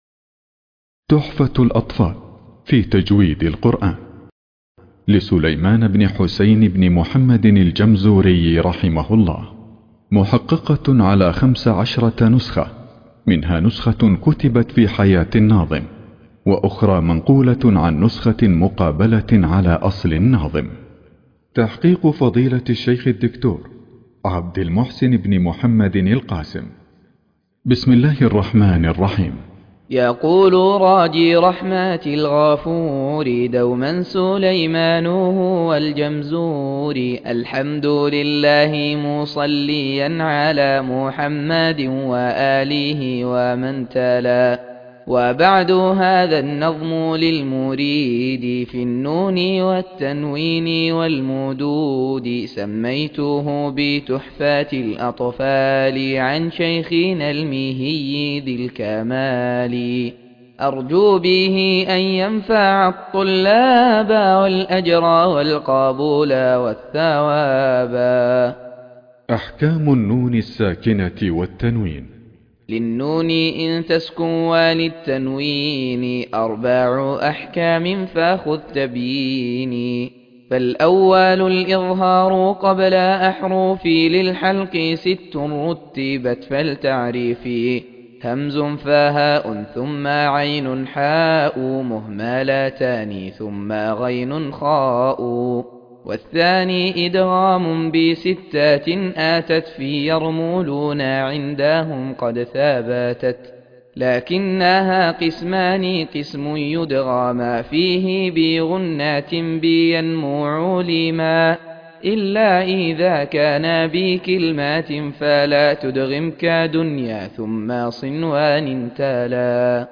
تحفة الأطفال في تجويد القرآن _ قراءة